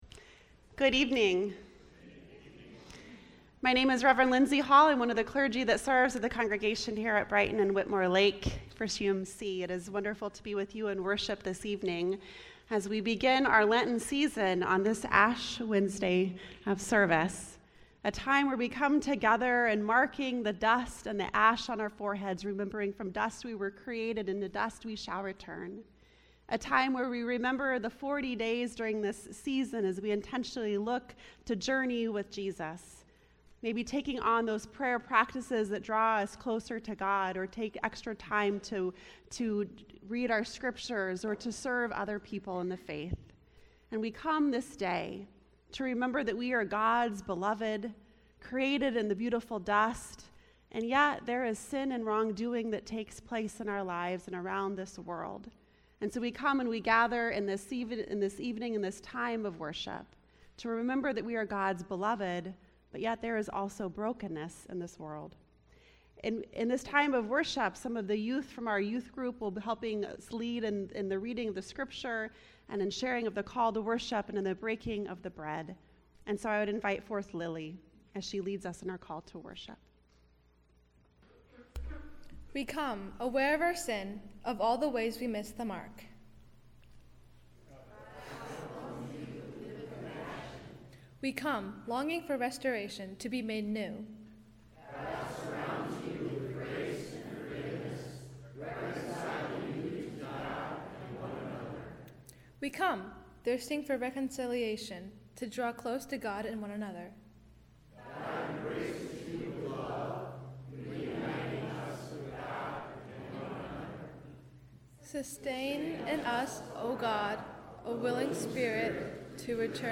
"Ash Wednesday" - First United Methodist Church-Brighton & Whitmore Lake